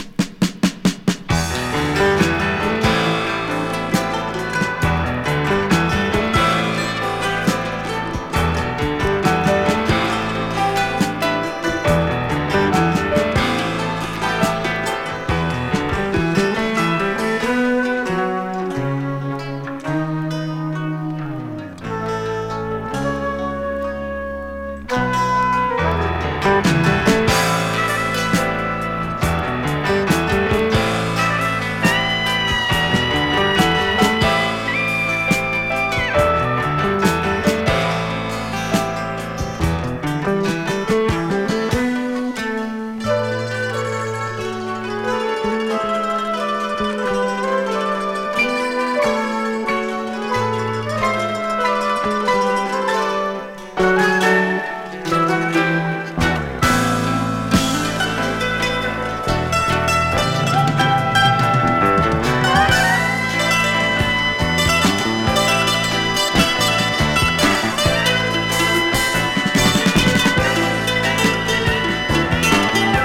針を置いた瞬間から、優しい雰囲気に包まれます、、、泣。'